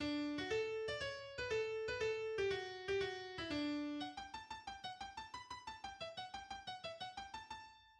en ré majeur
Genre Symphonie
Introduction du Molto allegro :